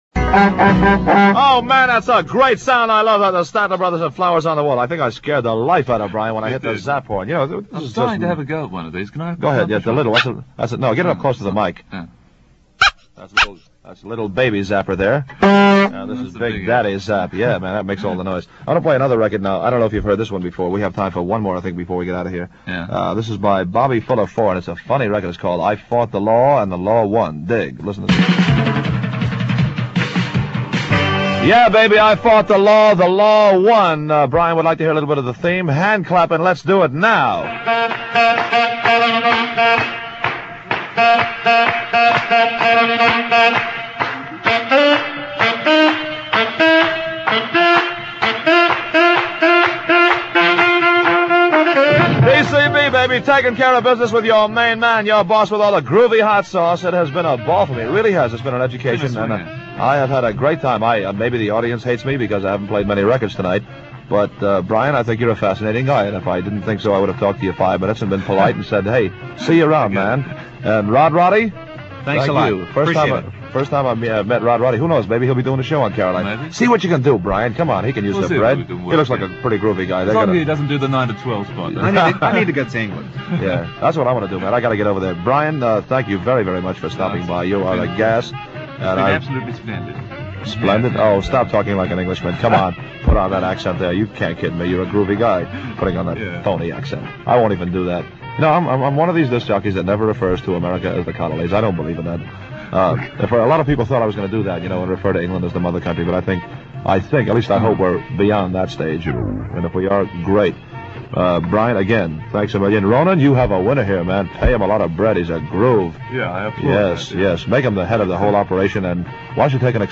WMCA ‘good guy’ Jack “Jake” Spector, one of the top broadcasters in New York City, provided taped shows for Radio Caroline during 1965 and early 1966.